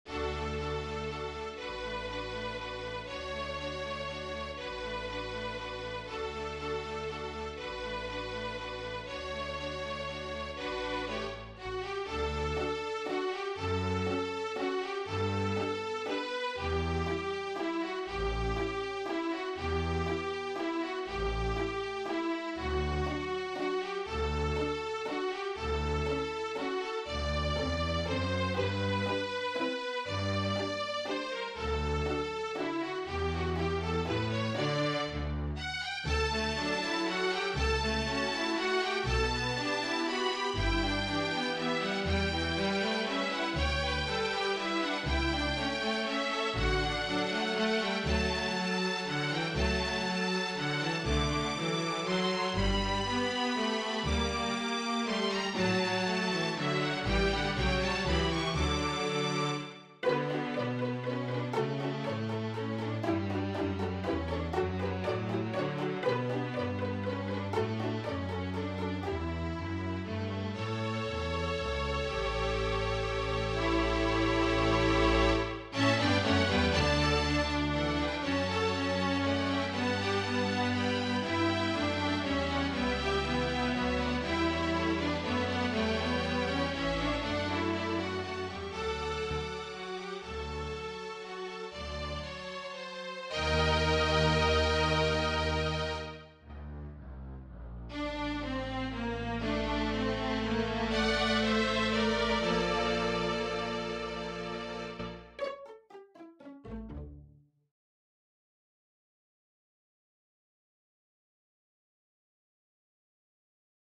Waltz
Standard String Orchestra